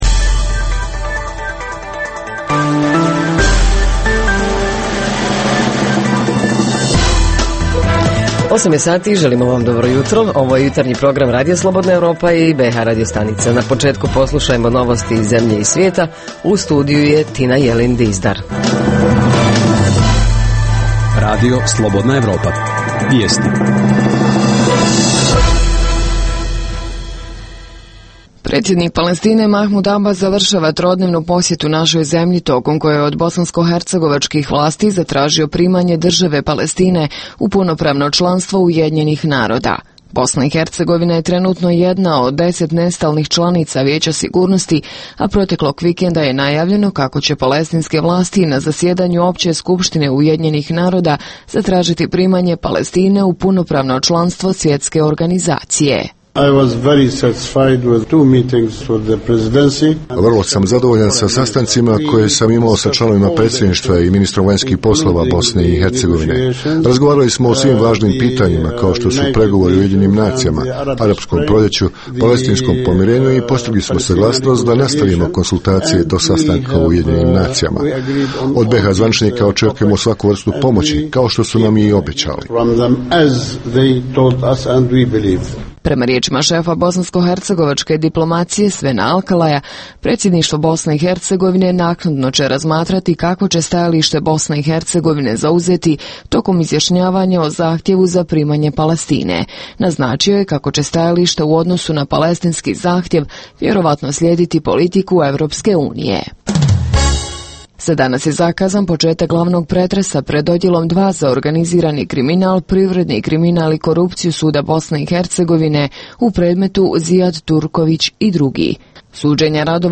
- sve ono što ovih dana nudi neki interesantan, edukativan ili zabavan sadržaj Reporteri iz cijele BiH javljaju o najaktuelnijim događajima u njihovim sredinama.
Redovni sadržaji jutarnjeg programa za BiH su i vijesti i muzika.